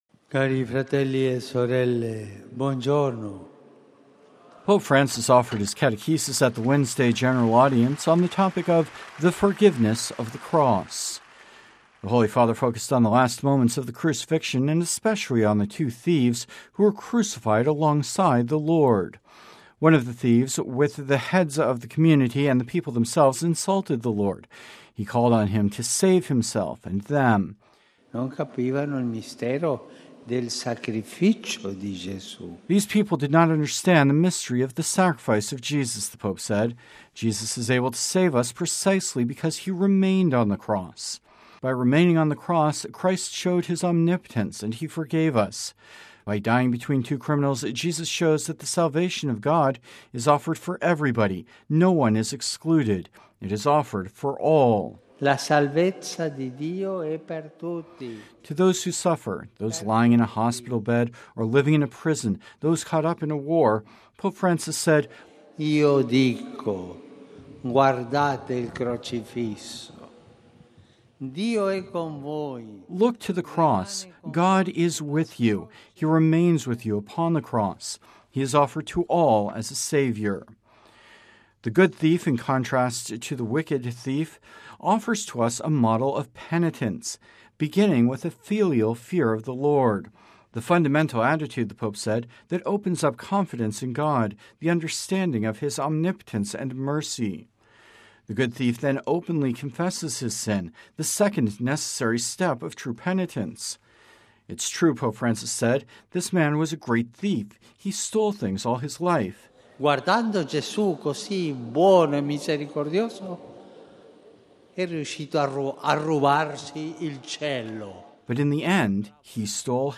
(Vatican Radio) Pope Francis offered his catechesis at the Wednesday General Audience on the topic of “The Forgiveness of the Cross.”